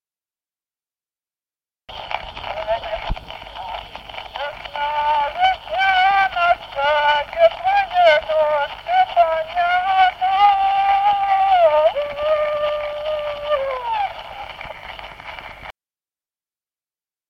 Народные песни Стародубского района «Весна, весняночка», весняная девичья.
с. Алейниково.